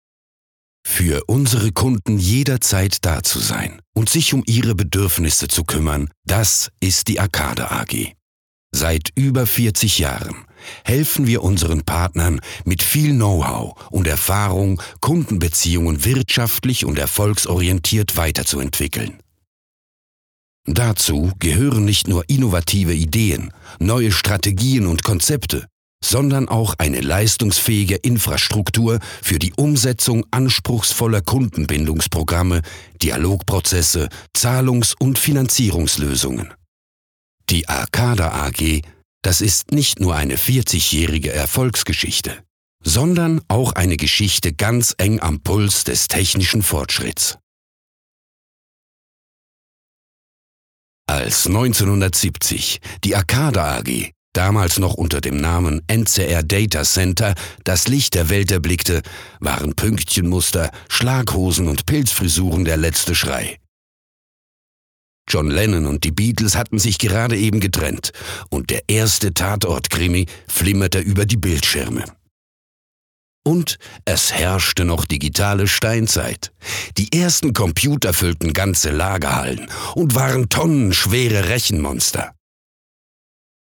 OFF Hochdeutsch (CH) Hörprobe 02